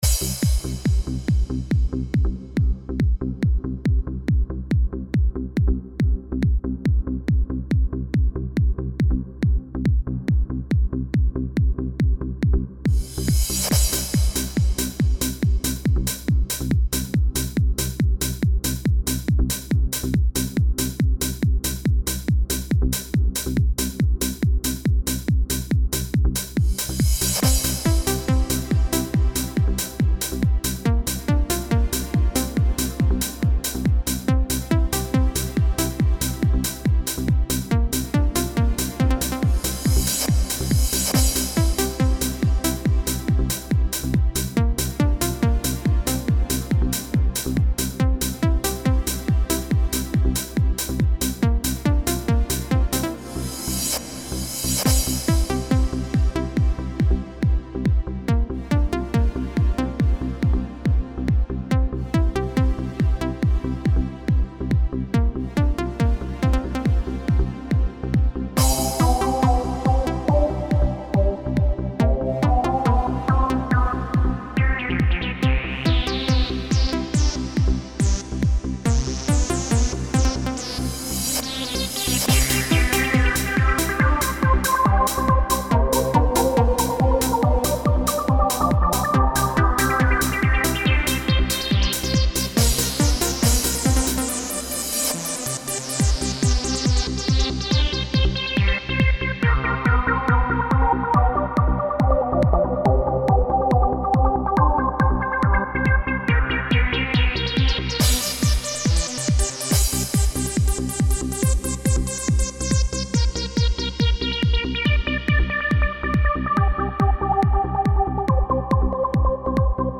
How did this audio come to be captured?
Composed in MULAB with a variety of VSTs and VSTis